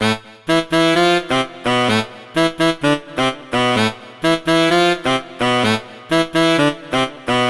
加勒比海黄铜
Tag: 128 bpm House Loops Brass Loops 1.26 MB wav Key : Unknown